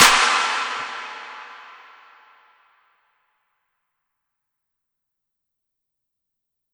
Tm8_Clap3.wav